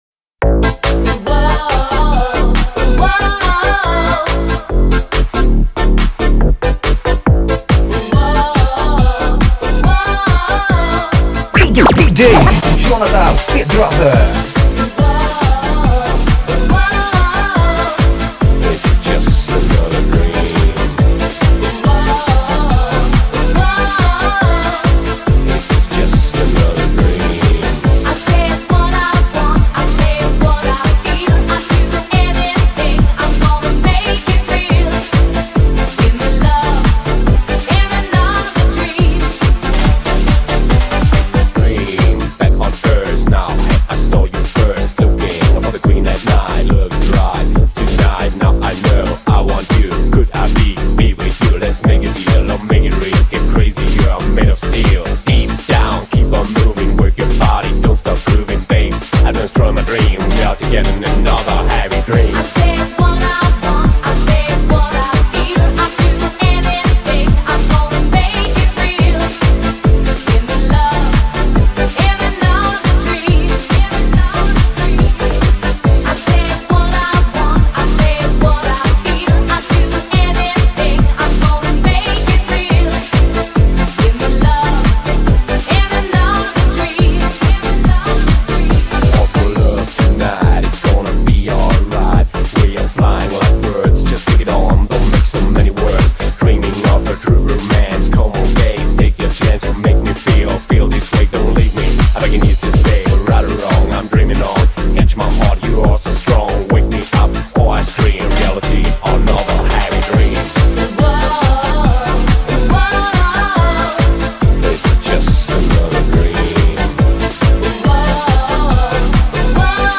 DANCE_ANOS_90_.mp3